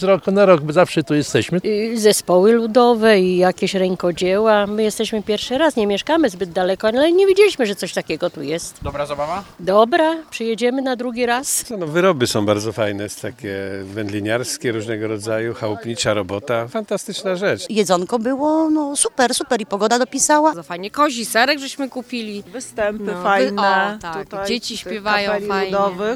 – Wspaniała zabawa i wiele atrakcji – mówią uczestnicy wydarzenia: